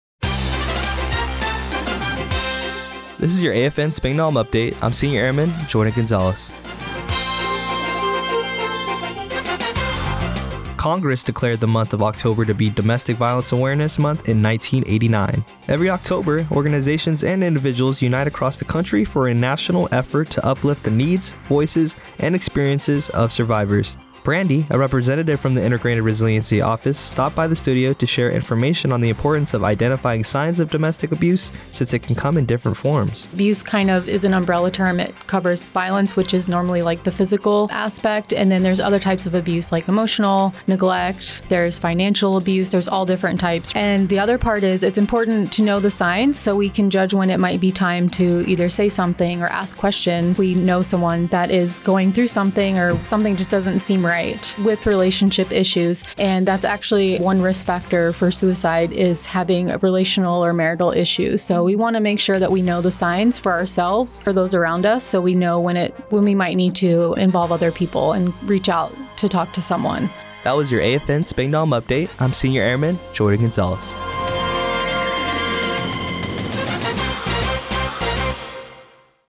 Radio news on 10-18-24 on Domestic Violence Awareness Month.